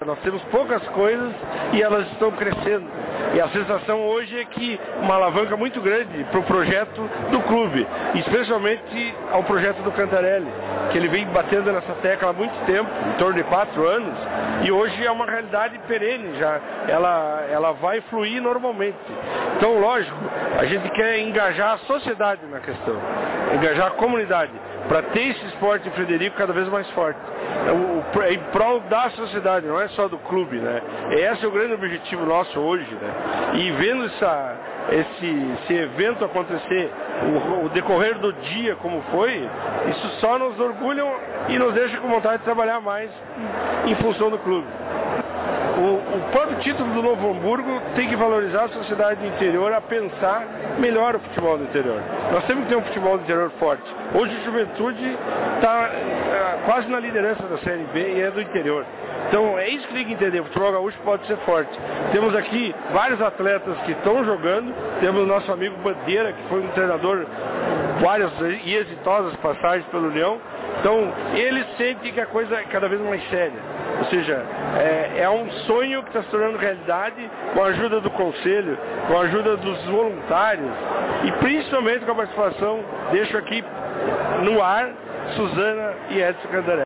Em um jantar comemorativo, o tricolor oficialmente empossou os ex-jogadores Danrlei e Índio como embaixadores no novo estádio do clube.